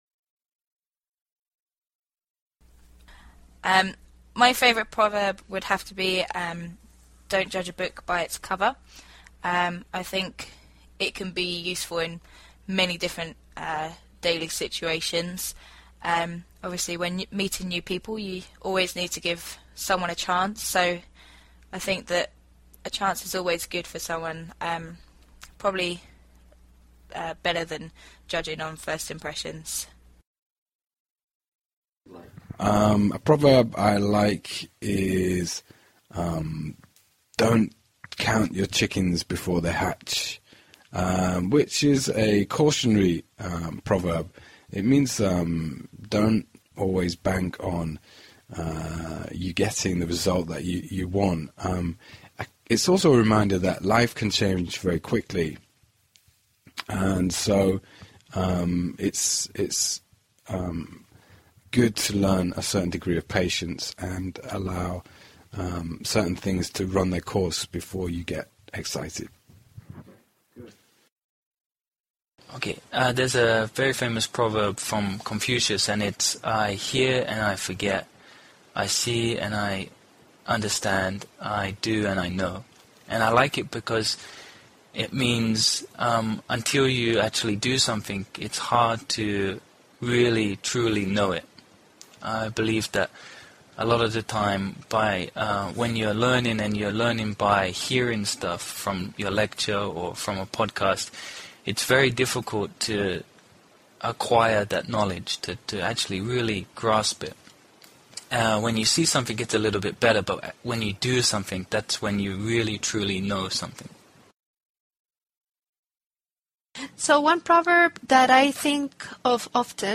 A proverb is a simple saying that explains a general rule or feeling. Six people share their favorites.